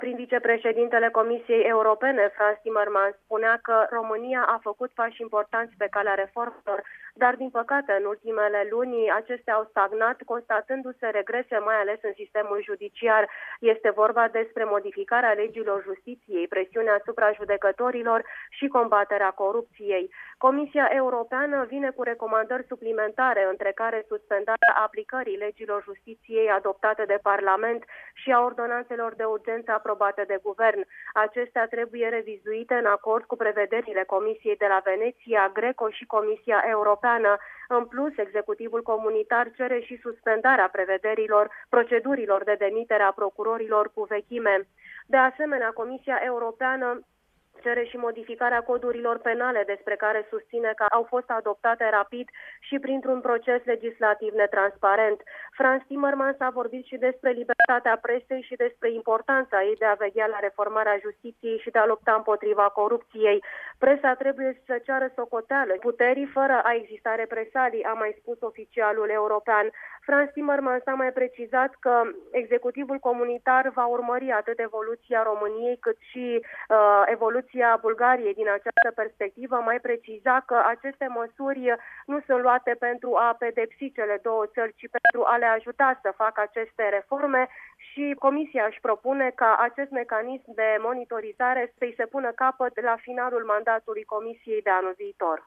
Frans Timmermans a susţinut o conferinţă de presă
Prim-vicepreşedintele Comisiei Europene, Frans Timmermans, a susţinut o conferinţă de presă la finalul reuniunii Colegiului comisarilor unde au fost discutate cele două documente.